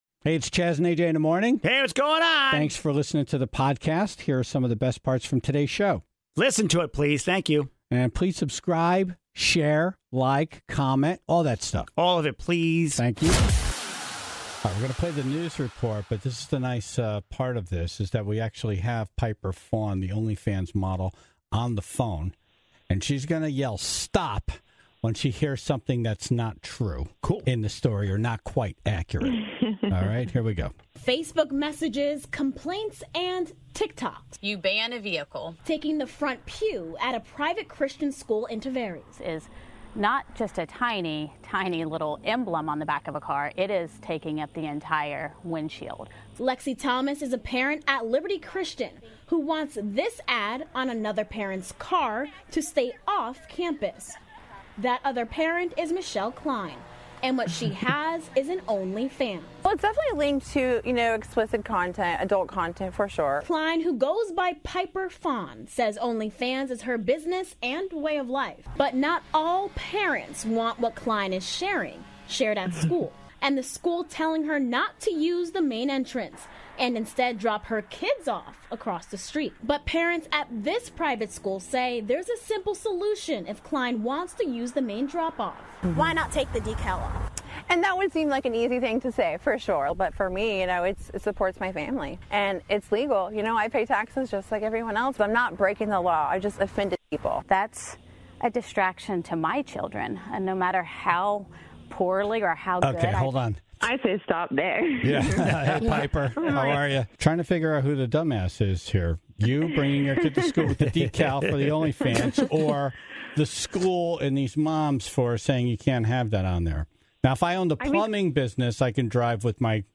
A master brewer was in studio